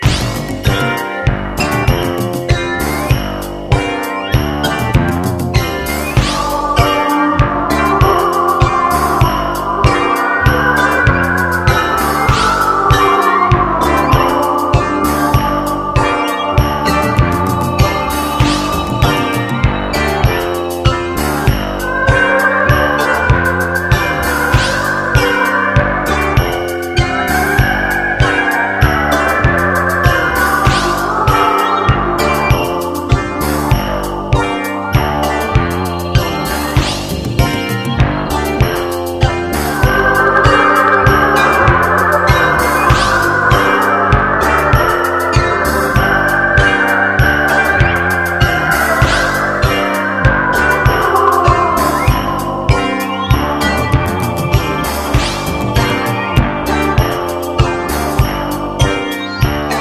ROCK / 70'S / PROGRESSIVE ROCK / JAZZ ROCK / CANTERBURY
耽美的でメロディアス、全編に渡って繊細にうねるポップさが全開のカンタベリー傑作！